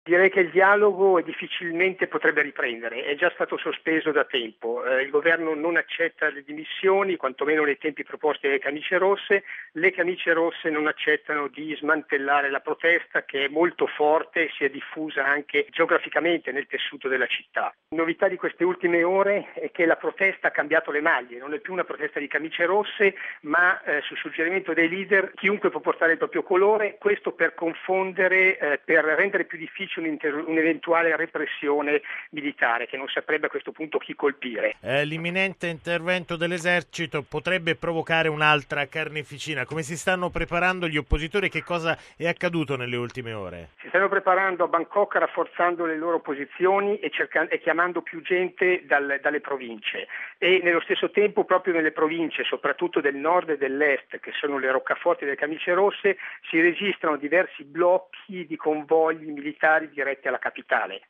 raggiunto telefonicamente a Bangkok